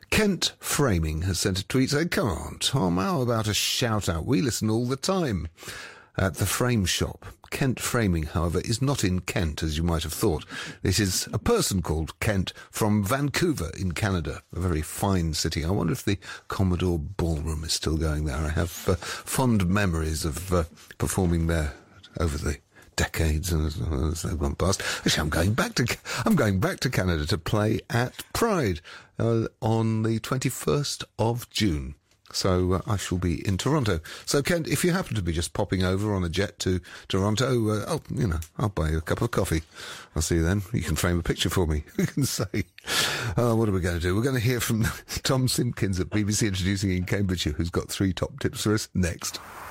Last weekend I sent in a tweet to say “hello” to Tom Robinson while he hosted his program “Introducing”.